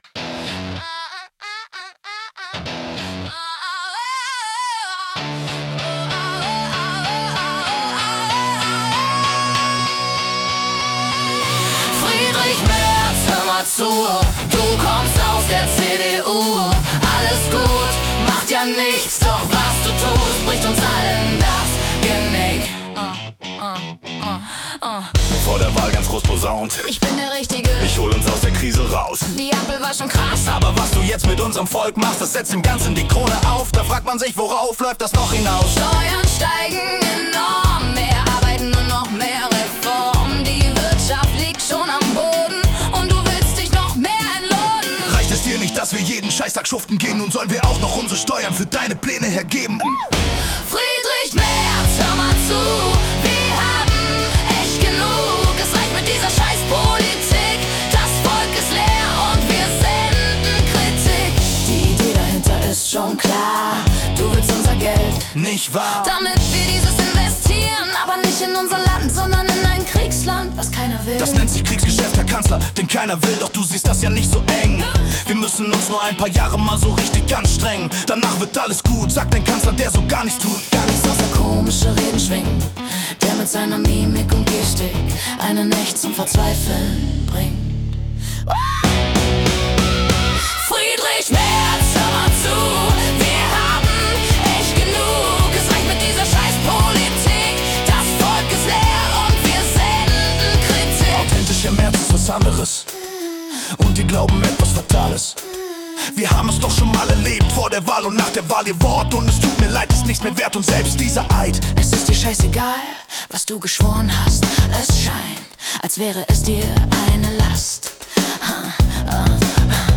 Hier eine Auswahl an Musik, die durch KI erzeugt wurde.